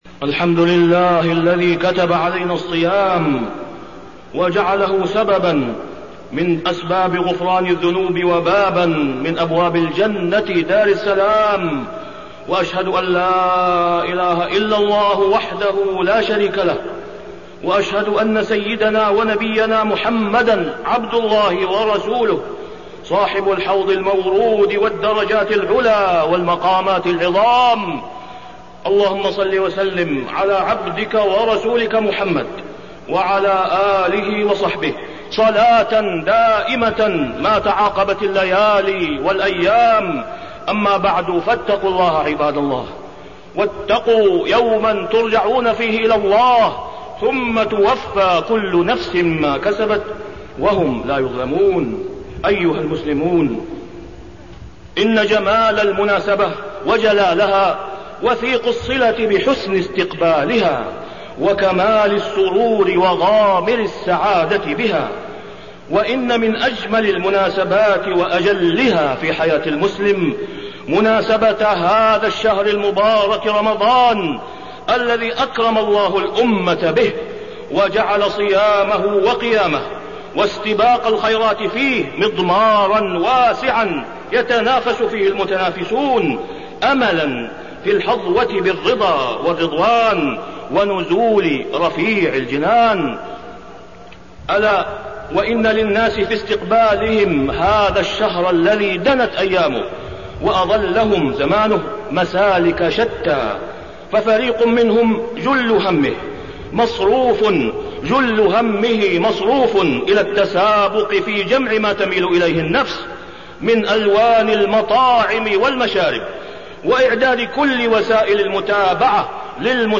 تاريخ النشر ٢٨ شعبان ١٤٢٩ هـ المكان: المسجد الحرام الشيخ: فضيلة الشيخ د. أسامة بن عبدالله خياط فضيلة الشيخ د. أسامة بن عبدالله خياط فضل شهر رمضان المبارك